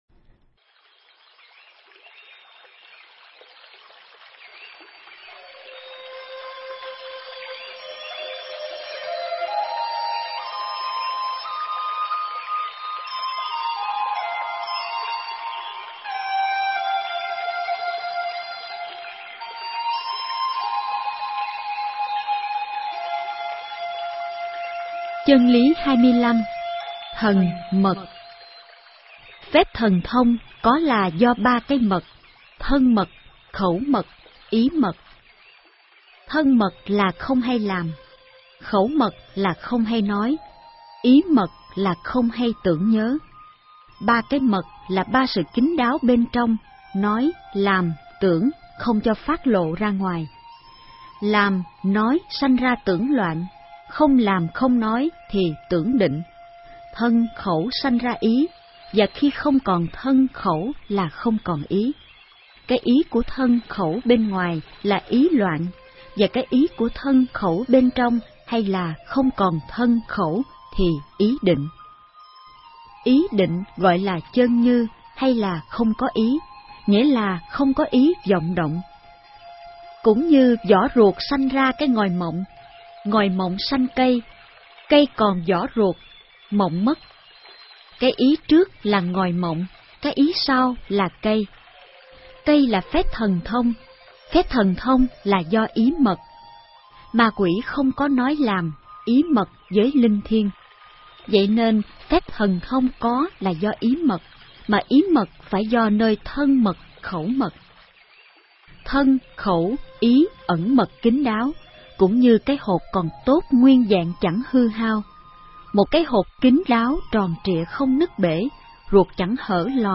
Nghe sách nói chương 25. Thần Mật